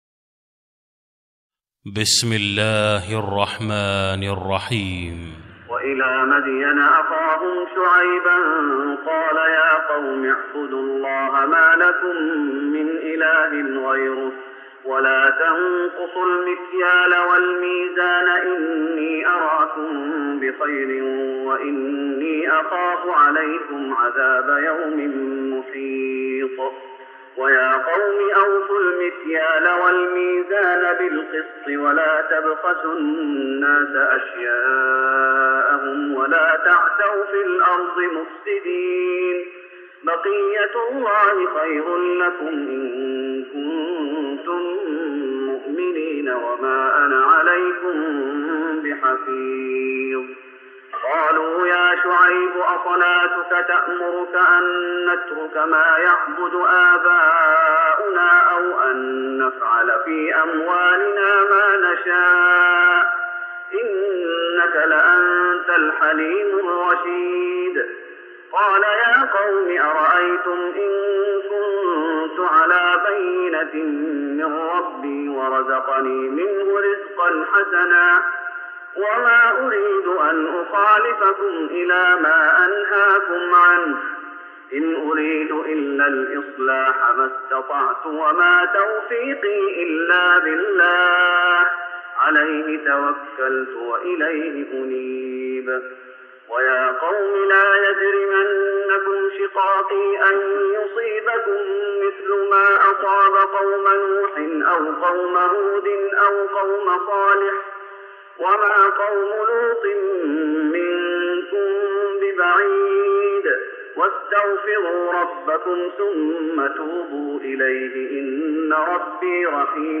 تراويح رمضان 1414هـ من سورة هود (84-123) Taraweeh Ramadan 1414H from Surah Hud > تراويح الشيخ محمد أيوب بالنبوي 1414 🕌 > التراويح - تلاوات الحرمين